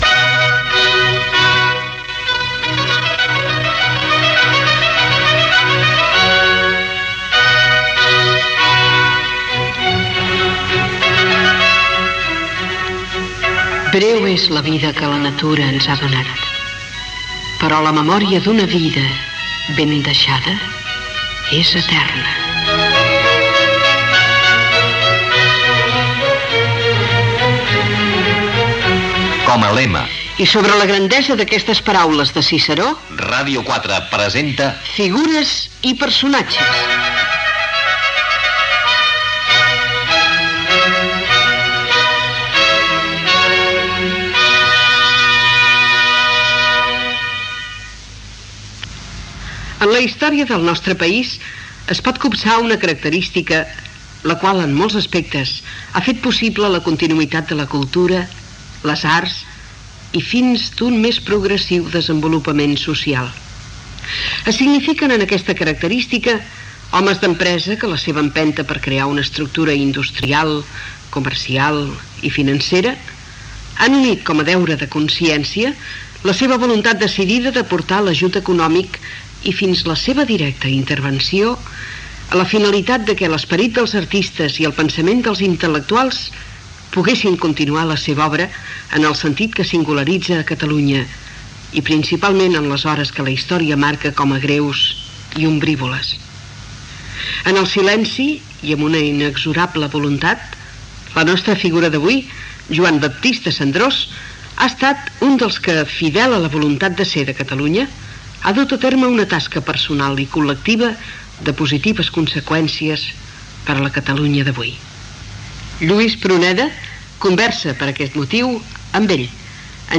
Careta, presentació del programa i entrevista